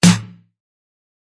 鼓的声音 " 黑色螺旋形小鼓 - 声音 - 淘声网 - 免费音效素材资源|视频游戏配乐下载
Snare声音我用一个小军鼓，可能是一个鼓掌，或者一点白噪声，将一个高大的汤姆分层。中档打得很好，顶端也很爽！